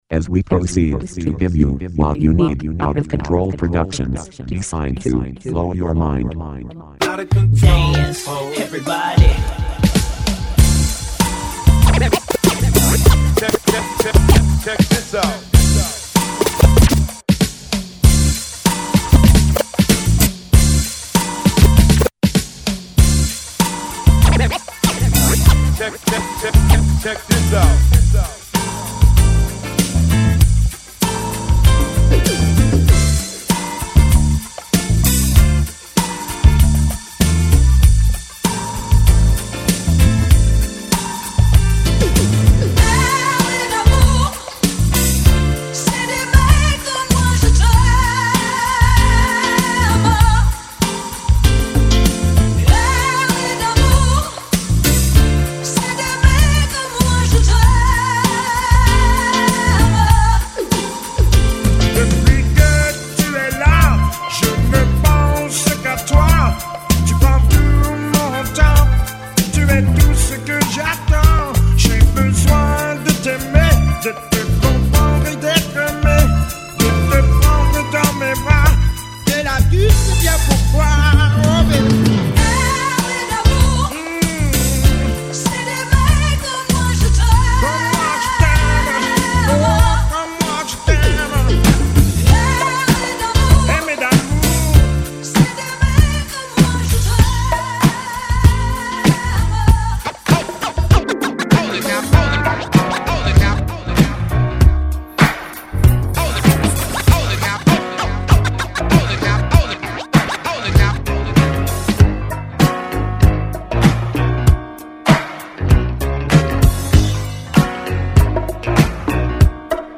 This little funk nugget
disco/afro funk